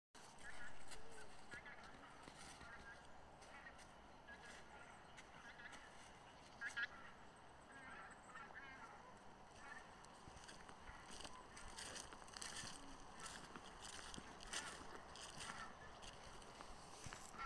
Birds -> Geese ->
Bean Goose, Anser fabalis
StatusVoice, calls heard